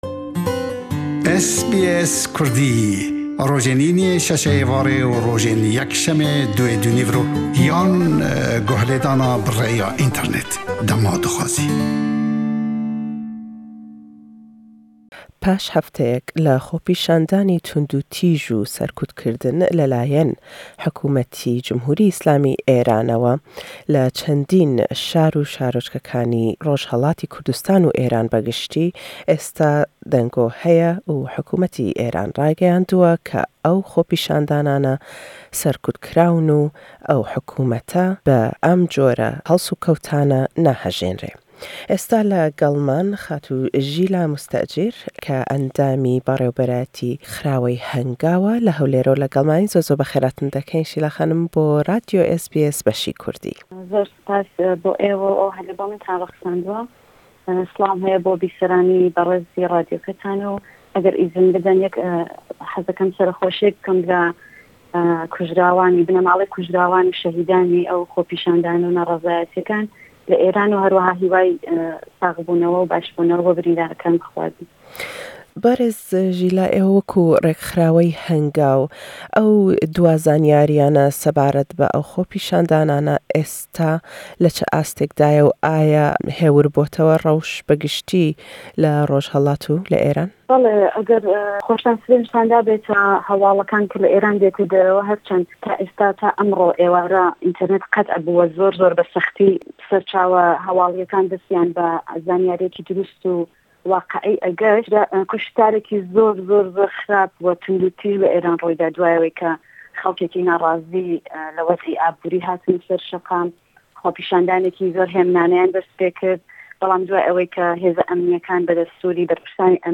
le gellman dedwêt sebaret be diwa zanîyarî le ser rewşî xopîşandanekanî Rojhellat û Êran be giştî.